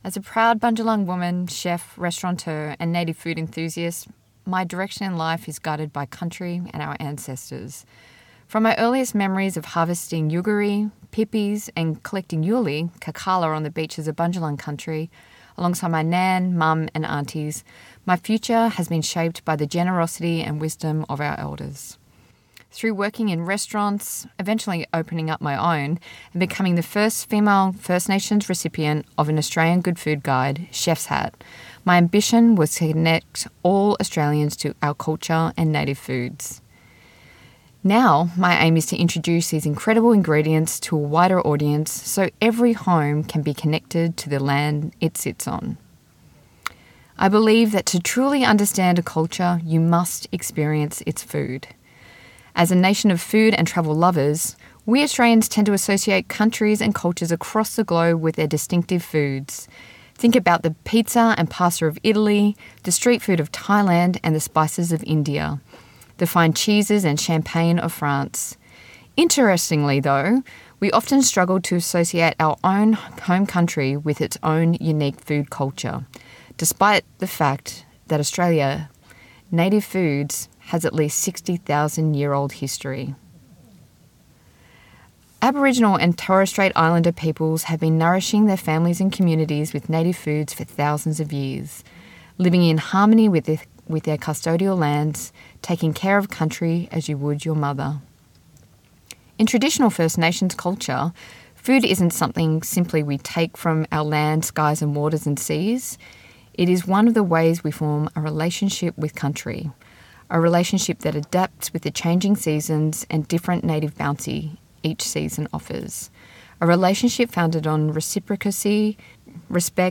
Recorded at the Byron Writers Festival 2024